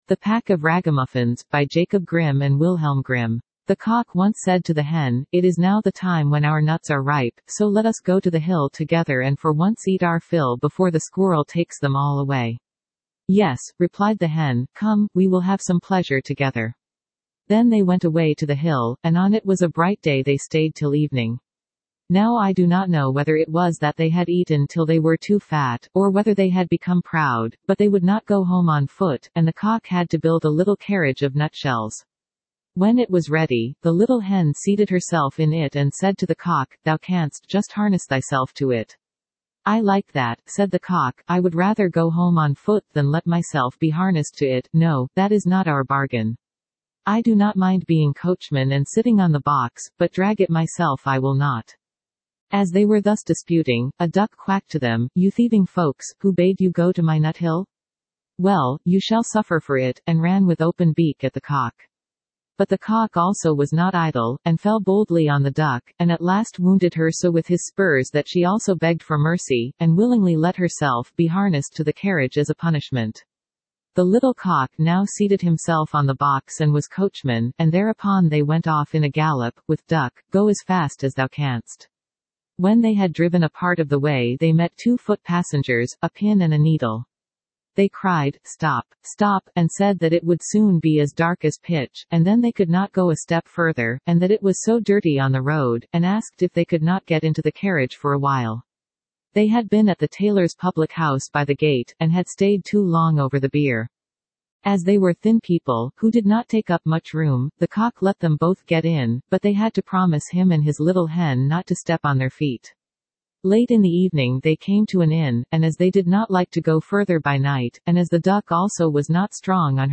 Standard (Female)